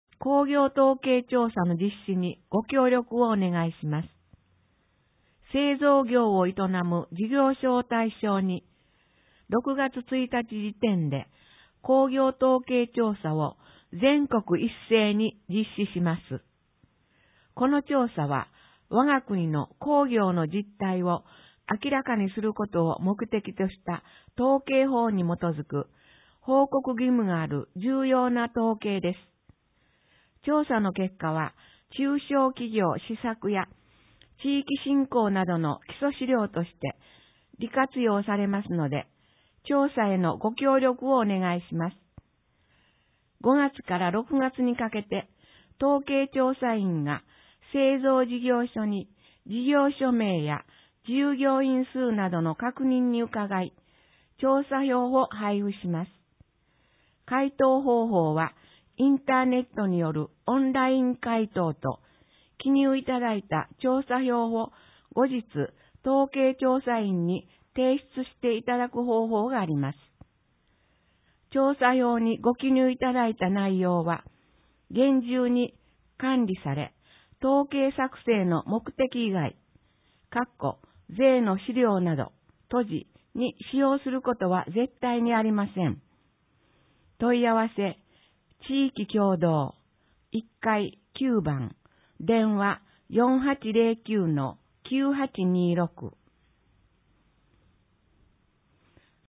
広報ひがしよどがわ5月号 1面 音声版 広報ひがしよどがわ平成30年5月号(MP3形式, 156.71KB) 東淀川区役所は「前例がない」とは口にしません!!(MP3形式, 28.39KB) 特集 頼れる地域の相談相手 民生委員・児童委員(MP3形式, 41.18KB) 総合区・特別区ってなんだろう？